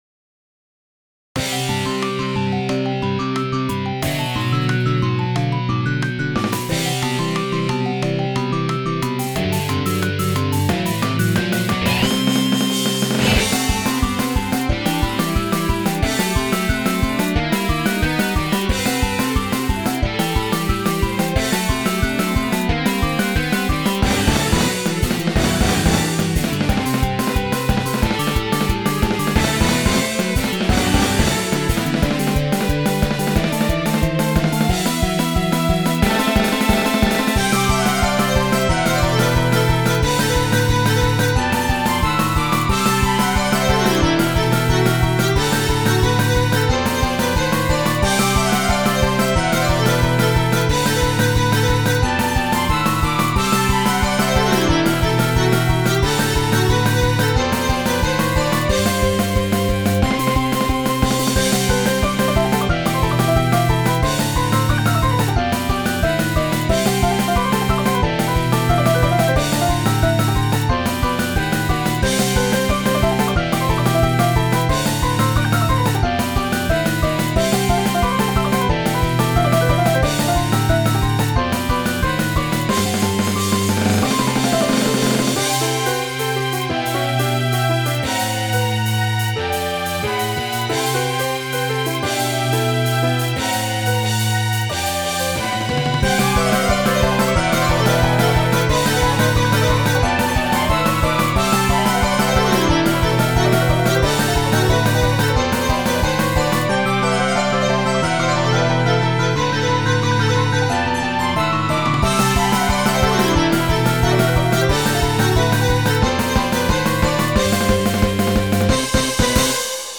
ジャンルPop